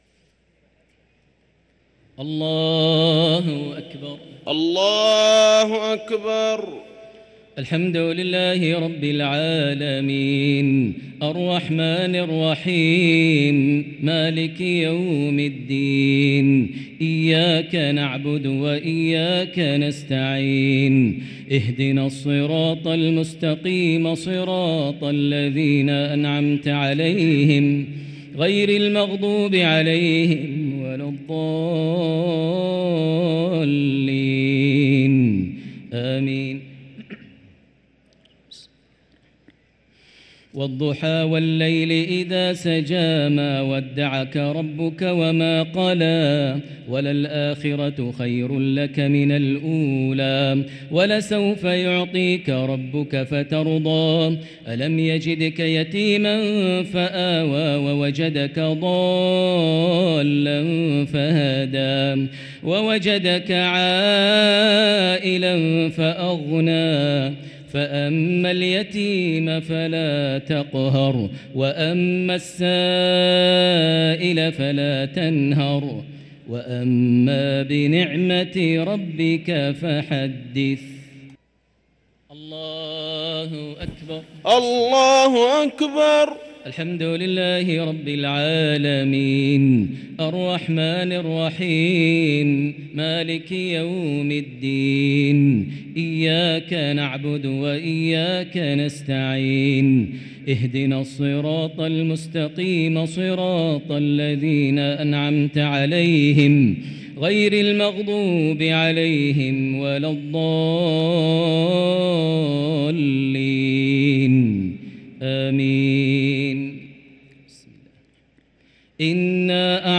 صلاة التراويح ليلة 17 رمضان 1444 للقارئ ماهر المعيقلي - الشفع والوتر - صلاة التراويح